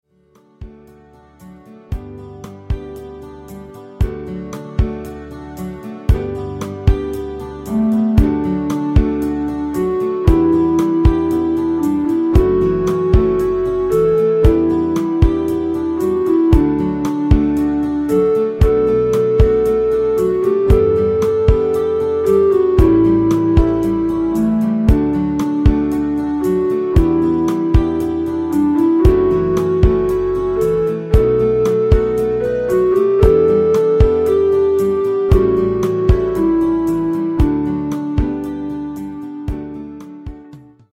(Backing + Melody)